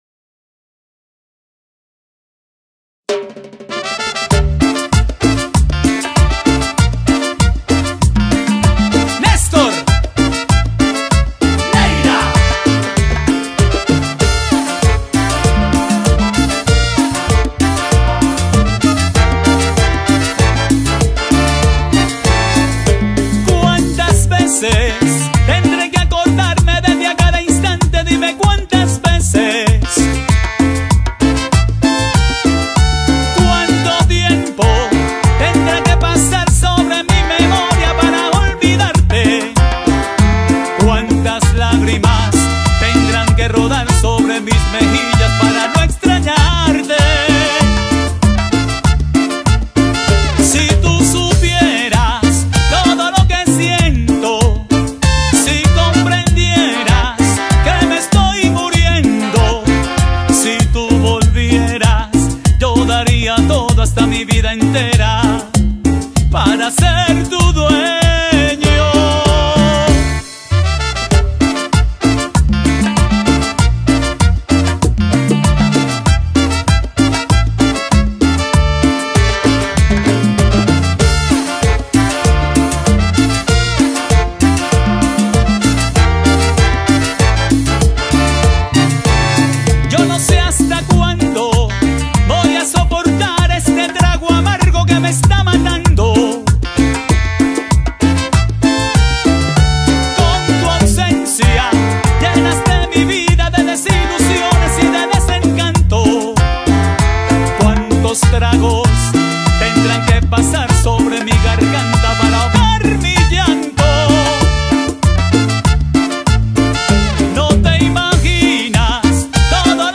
en apoderarse de la bandera de la cumbia colombiana actual y